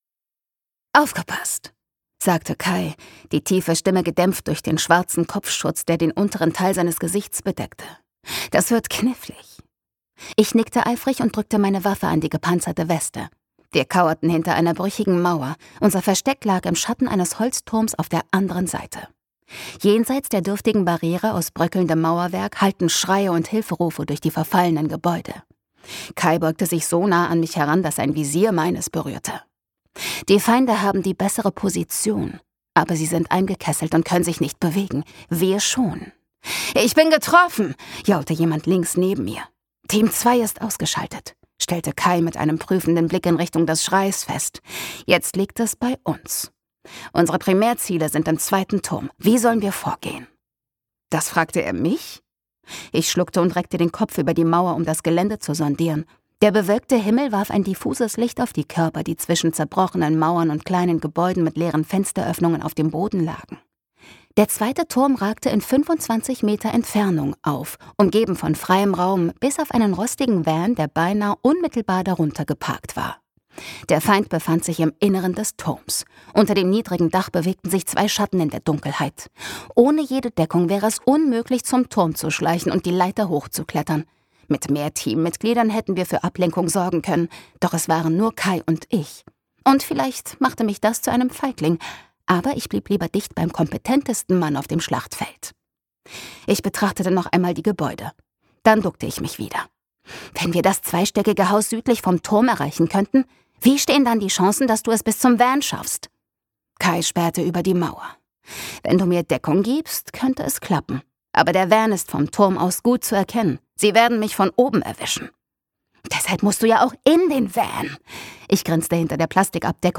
Das fünfte Hörbuch der Reihe und ein neues Abenteuer für Tori, Aaron, Kai und Ezra!
Gekürzt Autorisierte, d.h. von Autor:innen und / oder Verlagen freigegebene, bearbeitete Fassung.